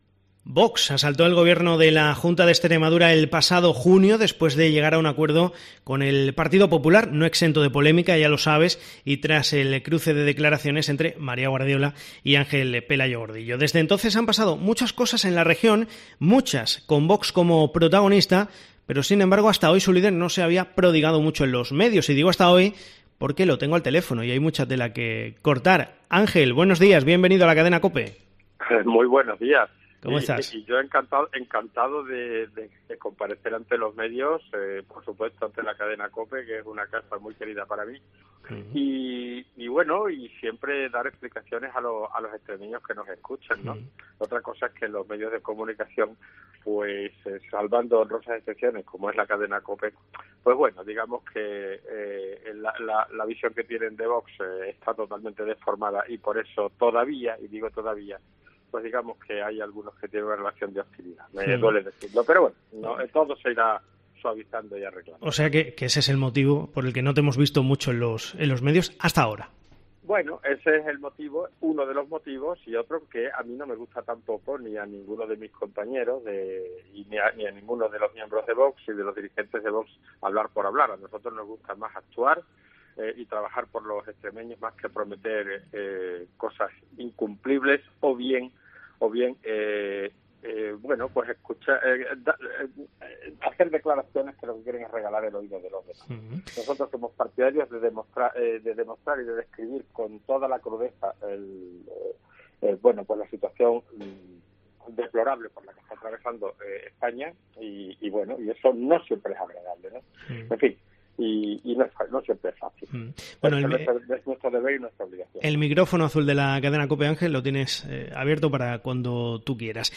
El líder de la formación de Abascal en Extremadura habla por primera vez desde las elecciones en COPE
Más de medio año después ha hablado aquí, en COPE Extremadura .